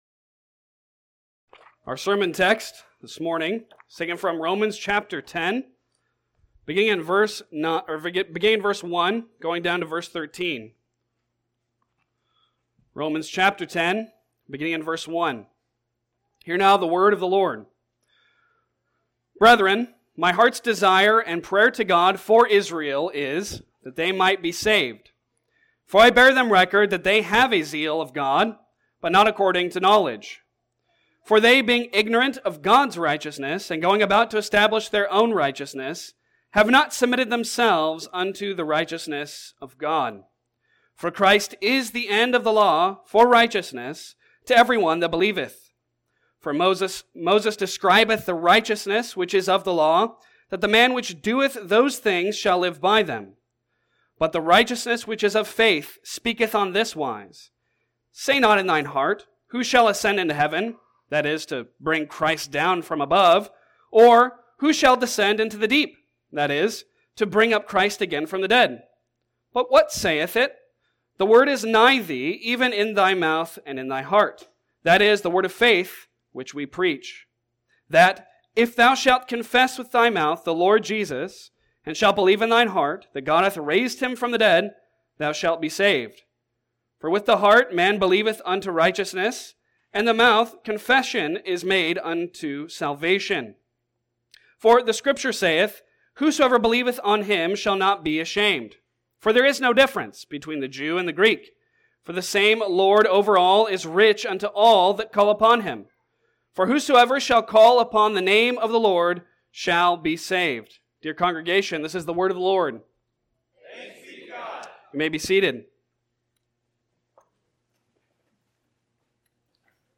Passage: Romans 10:1-13 Service Type: Sunday Sermon